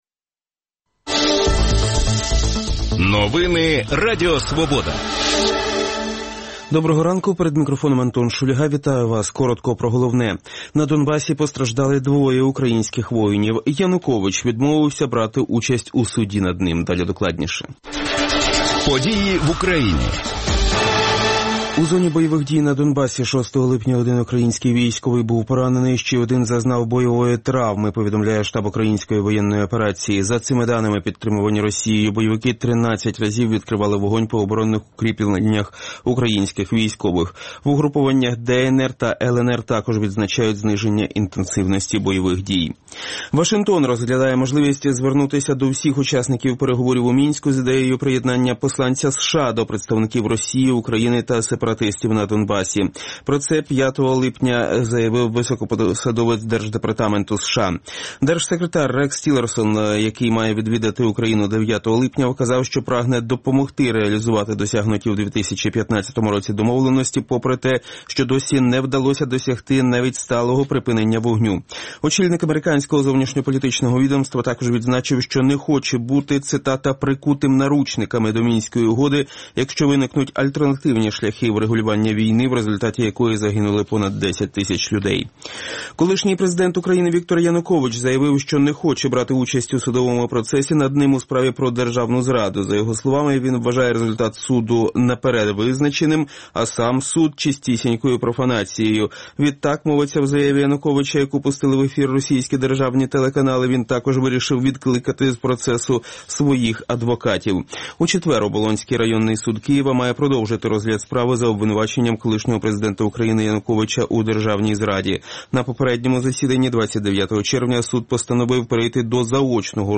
говоритиме з гостями студії